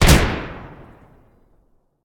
rifle3.ogg